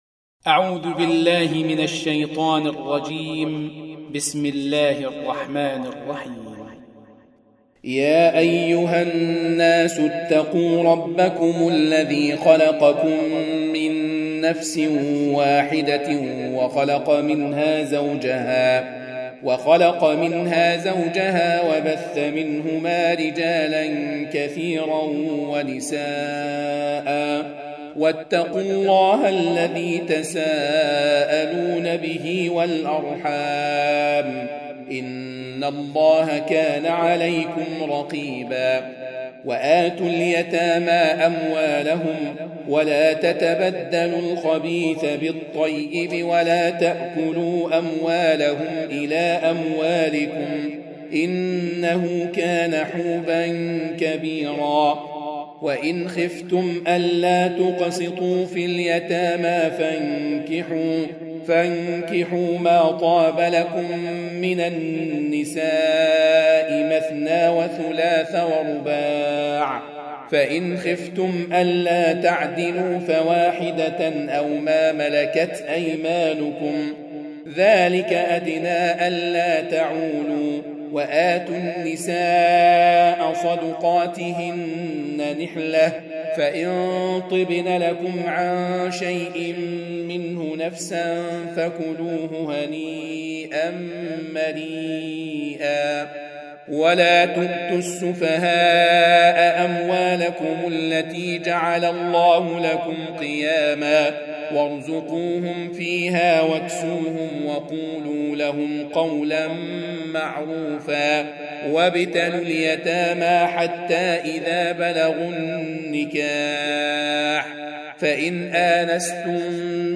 Audio Quran Tarteel Recitation
Surah Repeating تكرار السورة Download Surah حمّل السورة Reciting Murattalah Audio for 4. Surah An-Nis�' سورة النساء N.B *Surah Includes Al-Basmalah Reciters Sequents تتابع التلاوات Reciters Repeats تكرار التلاوات